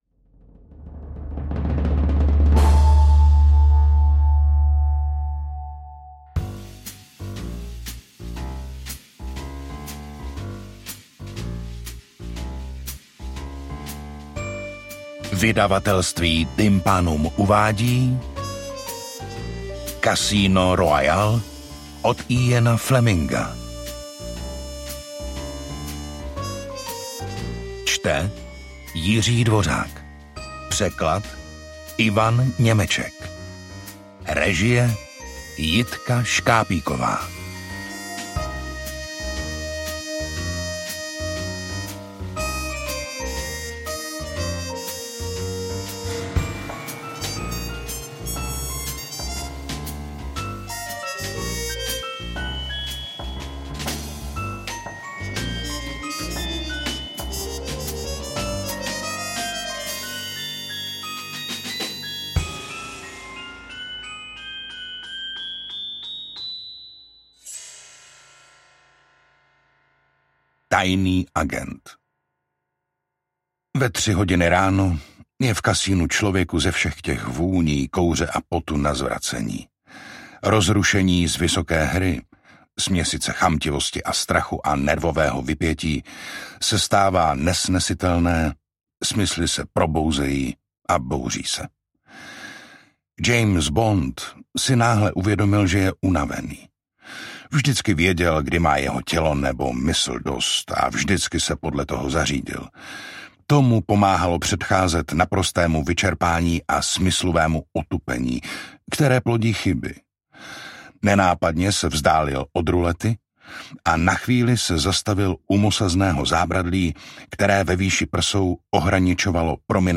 Interpret:  Jiří Dvořák
AudioKniha ke stažení, 27 x mp3, délka 6 hod. 5 min., velikost 348,9 MB, -